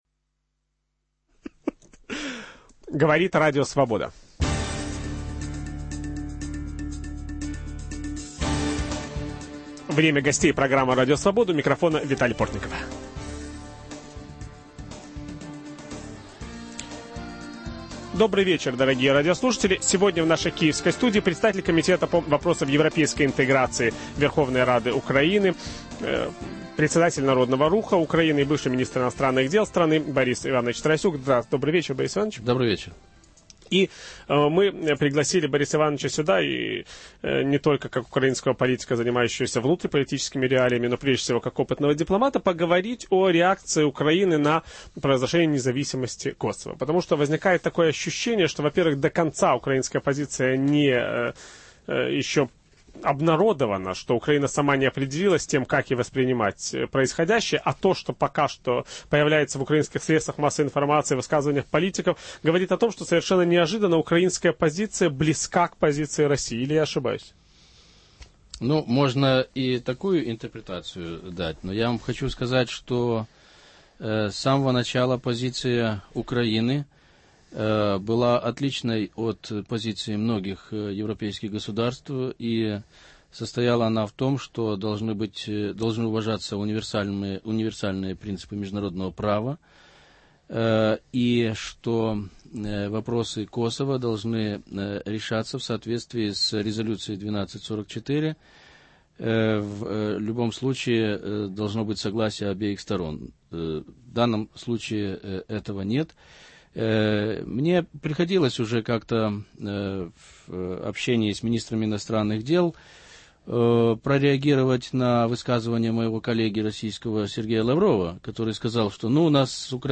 Об этой и других внешнеполитических темах ведущий программы Виталий Портников беседует с депутатом Верховной Рады Украины, бывшим министром иностранных дел страны Борисом Тарасюком